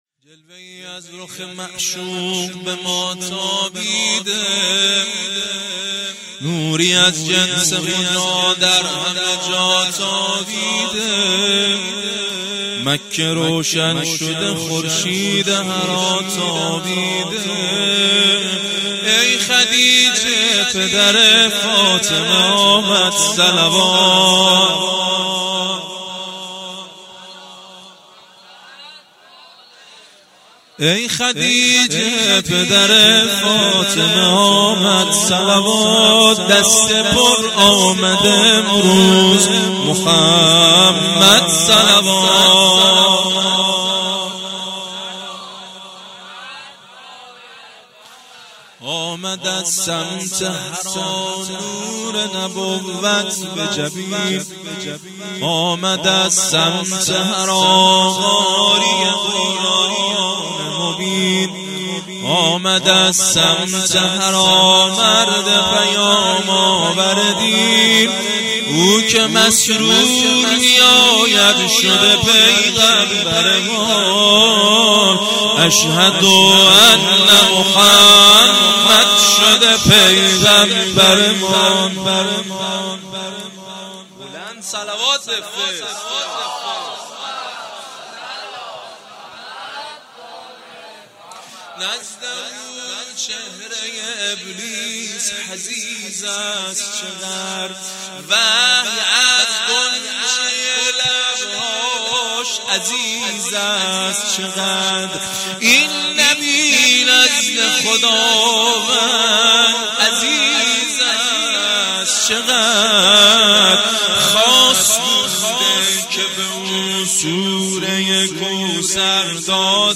0 0 مدح
جشن مبعث - جمعه24 فروردین 1397